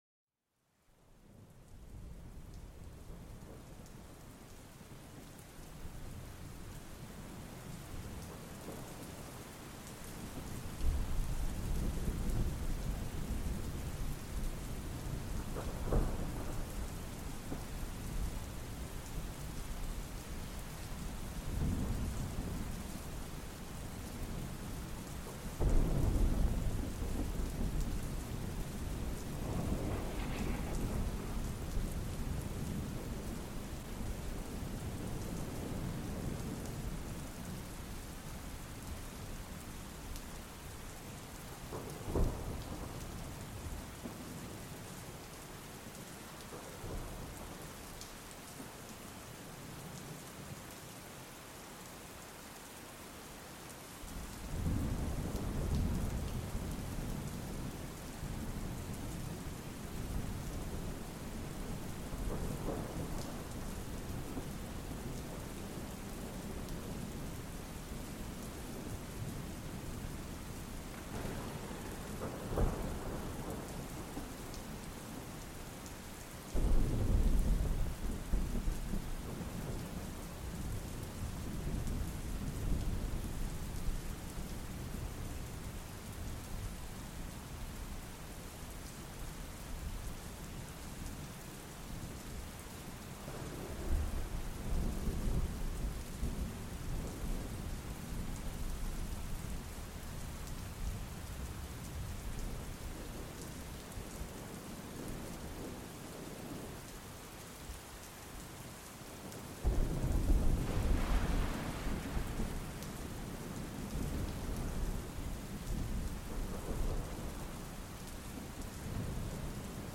Lluvia de tormenta intensa para una relajación profunda
Escucha el rugido relajante de una poderosa tormenta y deja que el sonido de la lluvia intensa te transporte. Este ruido blanco natural es perfecto para relajarse, meditar o dormir plácidamente.